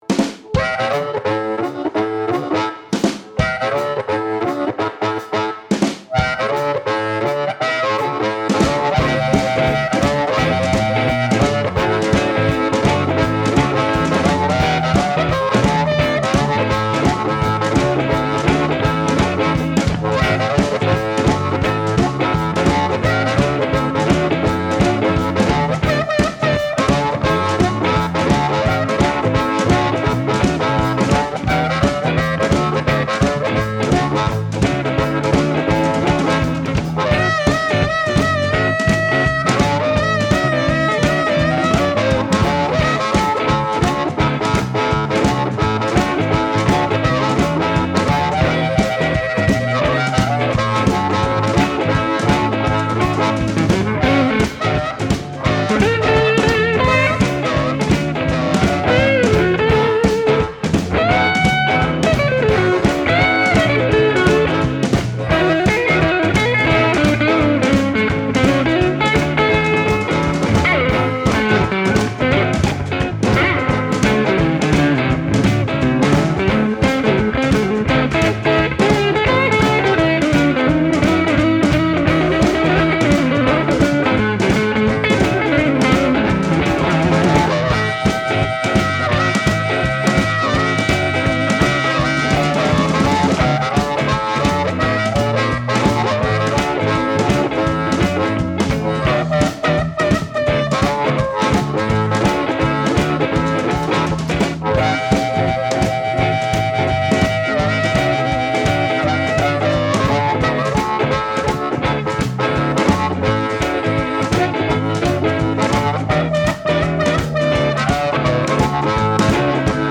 It goes from sparkling clean to brown and creamy distortion.